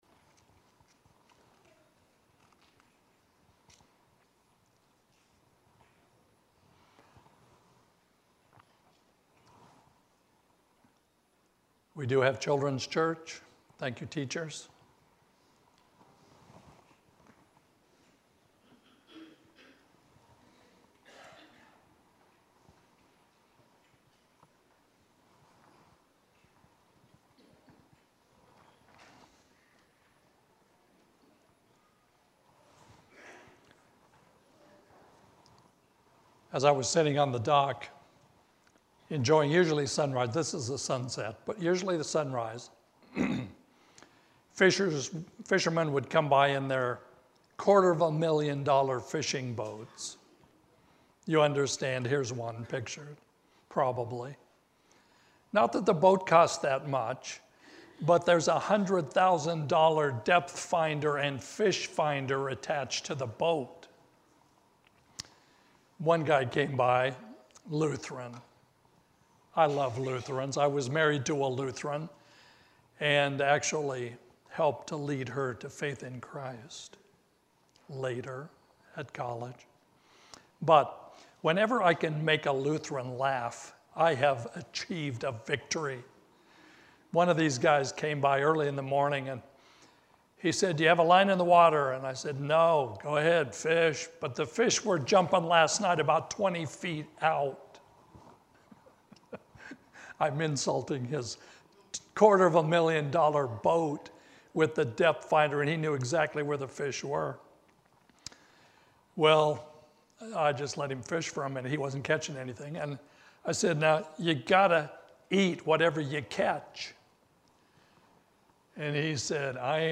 Sermon-June-9-2024.mp3